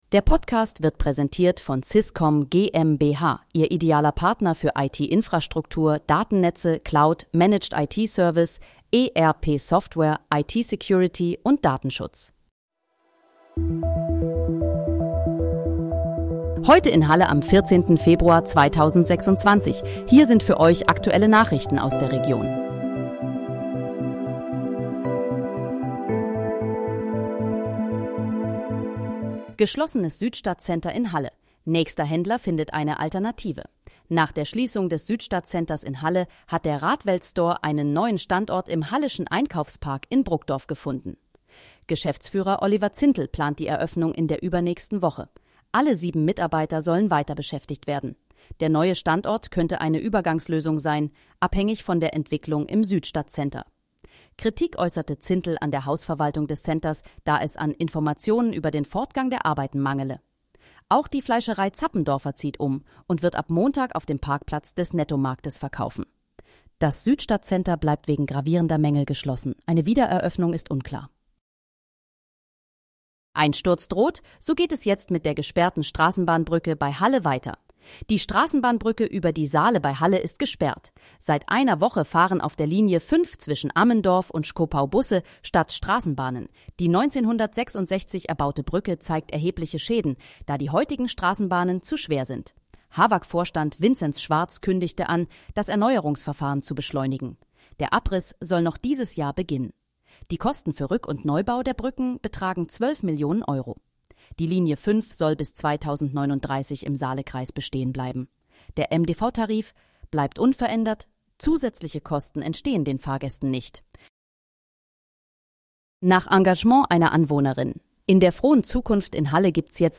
Heute in, Halle: Aktuelle Nachrichten vom 14.02.2026, erstellt mit KI-Unterstützung
Nachrichten